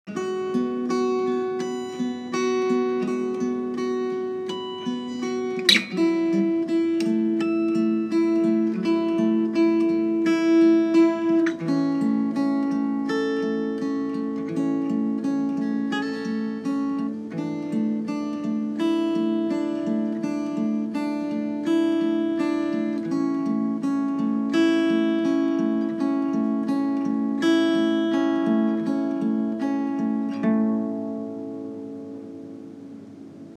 安慰，甚至是治愈，用简单而温暖的吉他音乐，让人们感受到亲切和温馨，找到共鸣和安慰 Comfort, even healing, using simple and warm guitar music to make people feel kind and warm, finding resonance and comfort